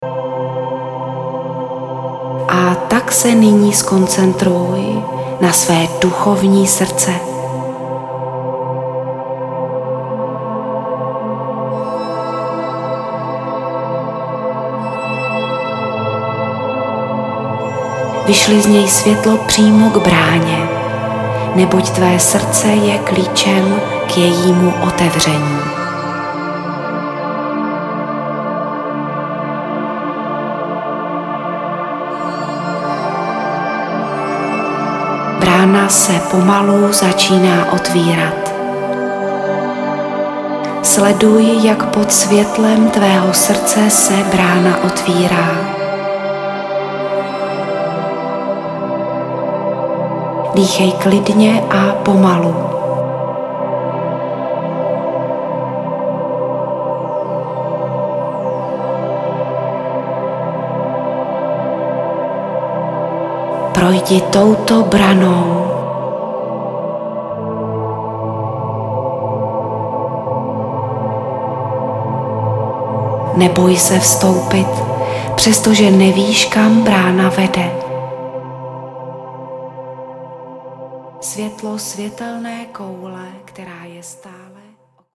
Nechte se vést mluveným slovem a snažte se vizualizovat a představovat si to, k čemu Vás řízená meditace vybízí.
Kategorie: Řízené meditace